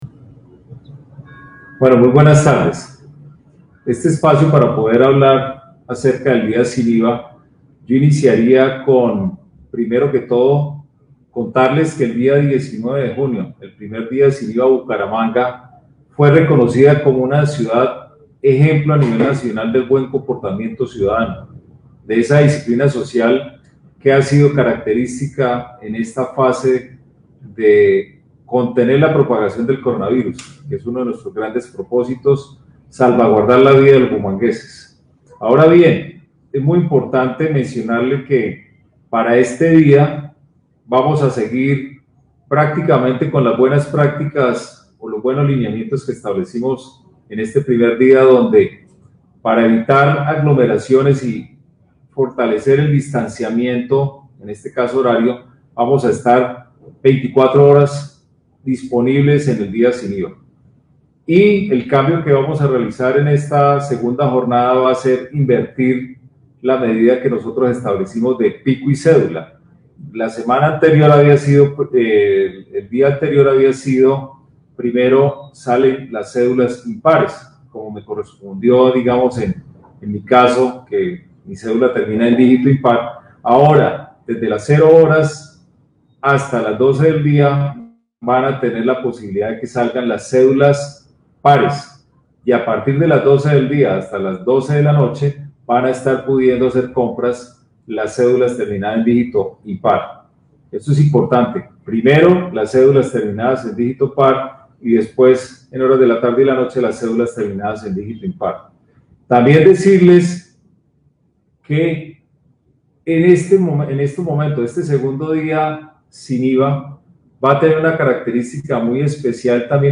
FB-LIVE-DIA-SIN-IVA-AUDIO.mp3